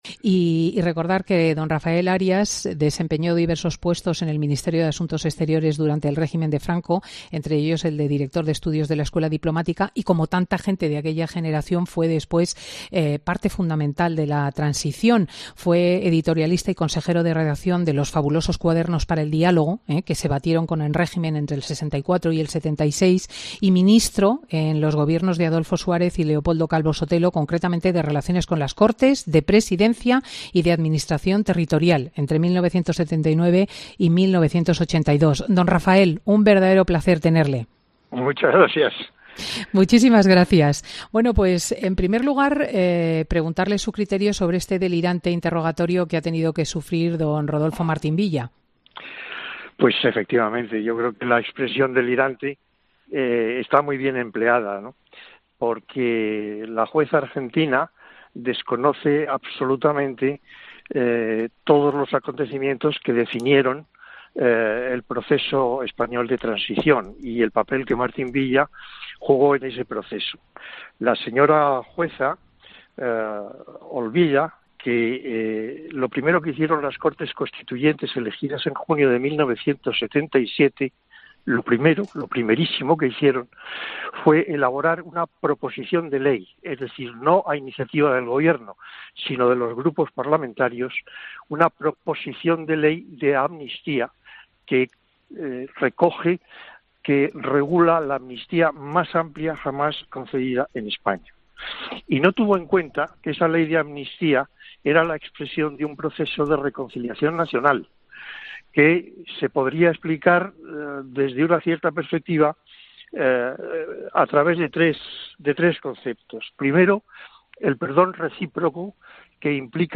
El pte. de la Fundación Transición Española y exministro con Adolfo Suárez y Calvo Sotelo ha visitado los micrófonos de Fin de Semana con Cristina